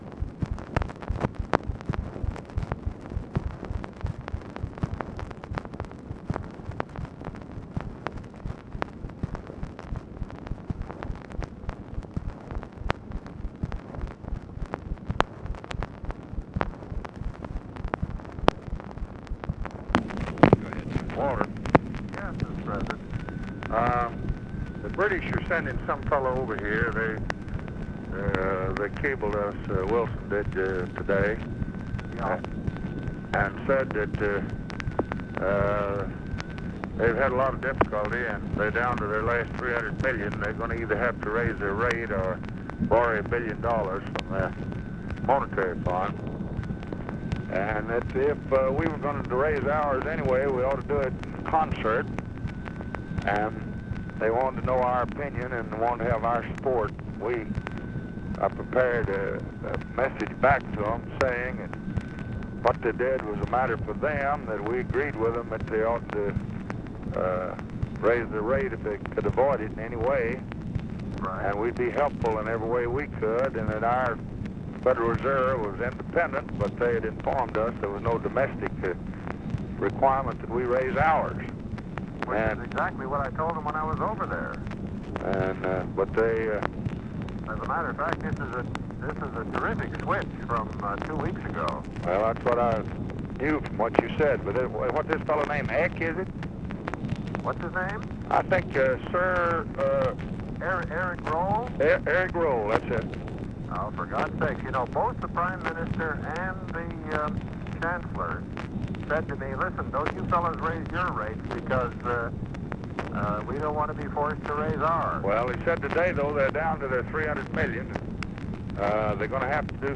Conversation with WALTER HELLER and SYLVIA PORTER, November 20, 1964
Secret White House Tapes